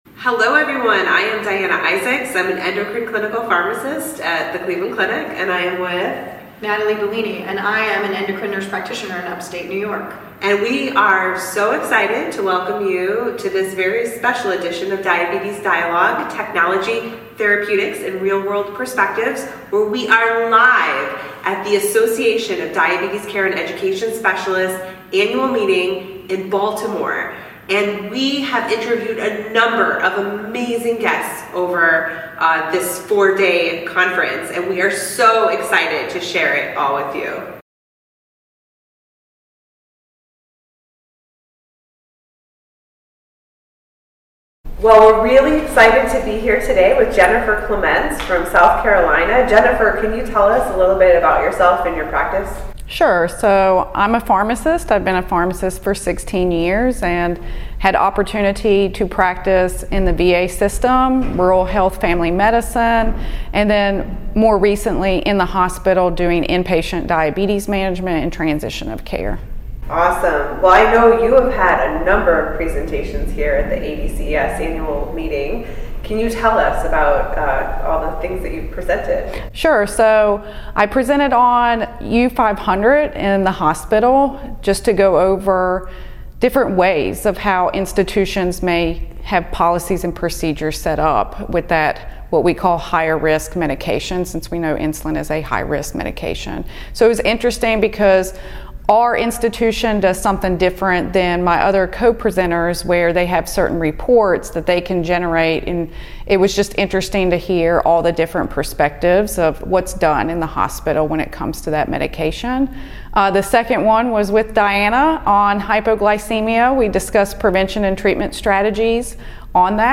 In this edition of Diabetes Dialogue, which was filmed on-site at ADCES 22